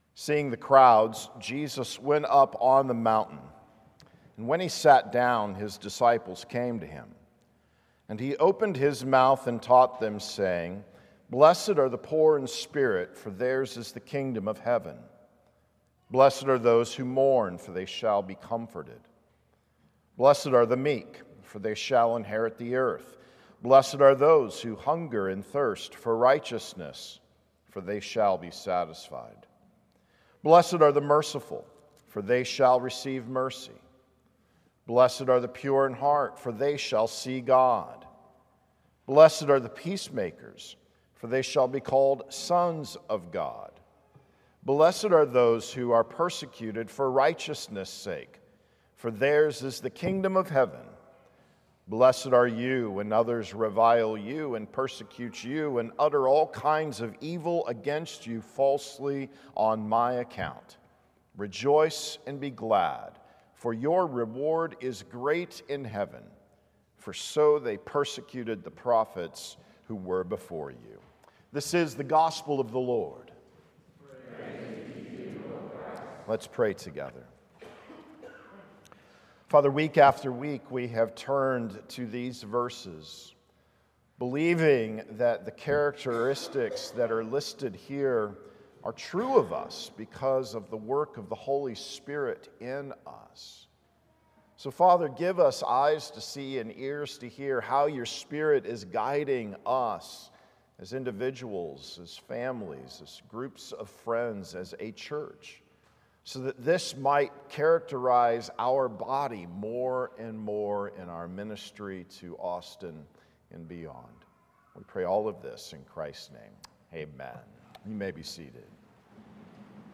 Redeemer Presbyterian Sunday Church Sermons in Audio Format